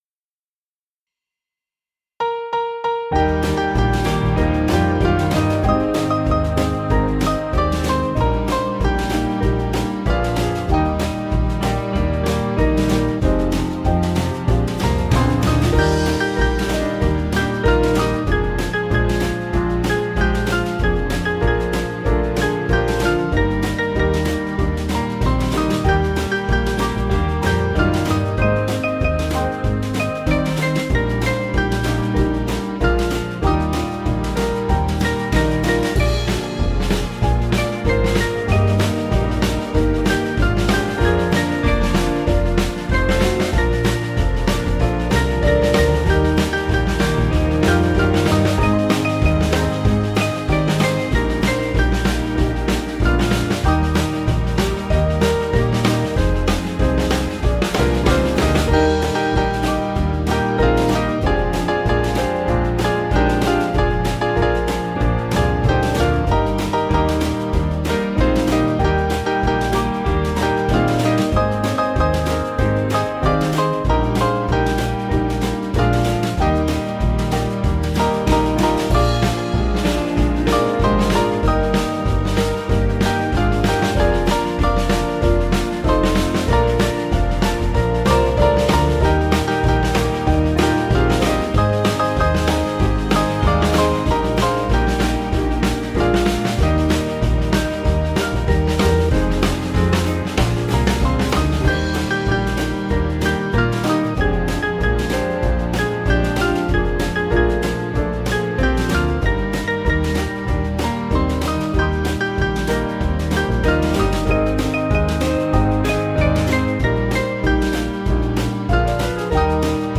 Small Band
(CM)   4/Eb 486.7kb